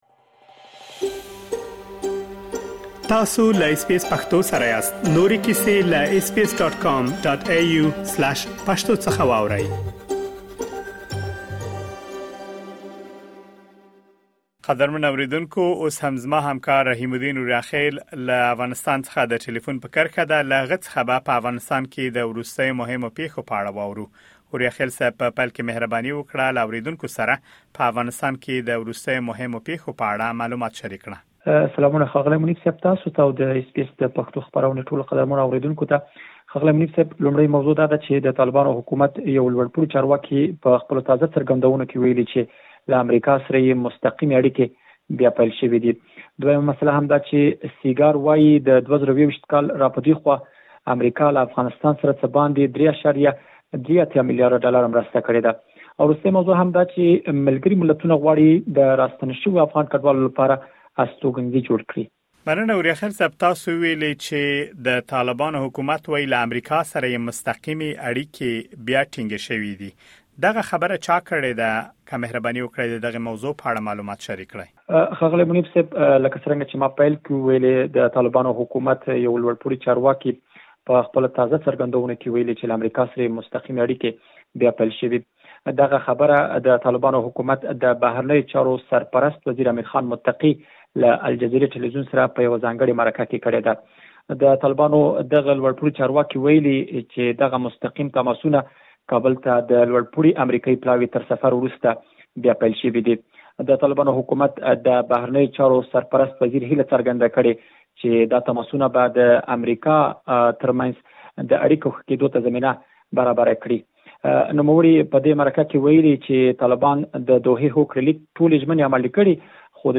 مهم خبرونه: د طالبانو حکومت وايي، له امریکا سره يې مستقیمې اړیکې بیا پیل شوې دي. سیګار وايي، له ۲۰۲۱ کال راپدېخوا امریکا له افغانستان سره څه باندې ۳.۸۳ میلیاردو ډالره مرسته کړې ده. ملګري ملتونه د راستنو شویو افغان کډوالو لپاره استوکنځي جوړوي.